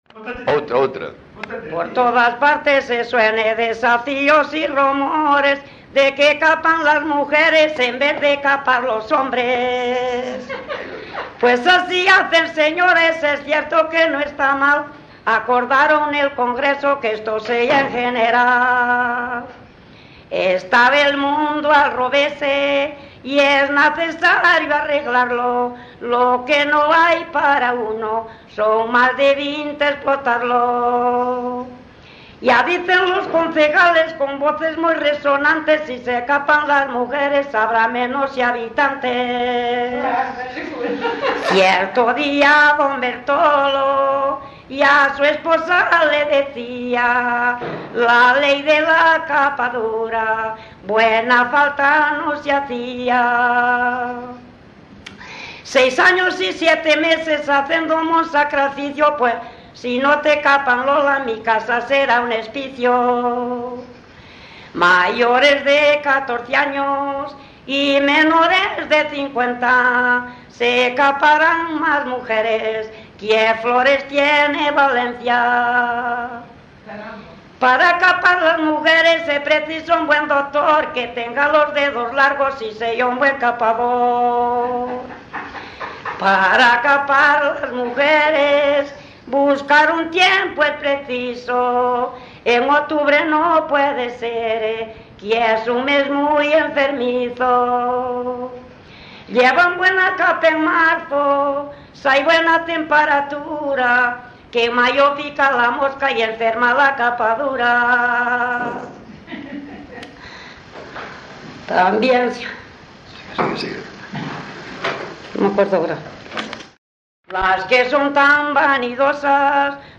Tipo de rexistro: Musical
LITERATURA E DITOS POPULARES > Cantos narrativos
Lugar de compilación: Pedrafita do Cebreiro - Veiga de Forcas (Santa María) - Veiga de Forcas
Soporte orixinal: Casete